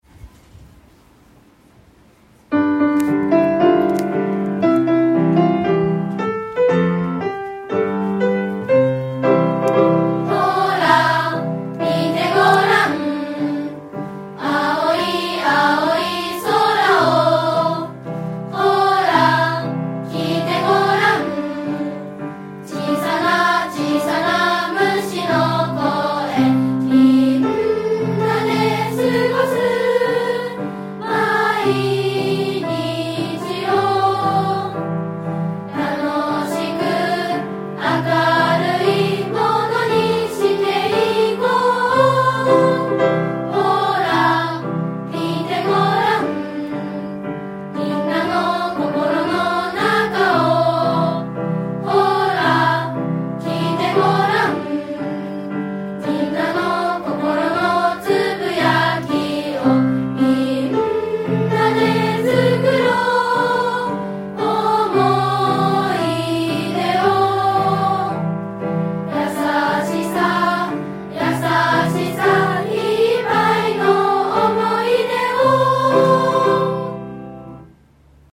児童会の歌（歌あり）.mp3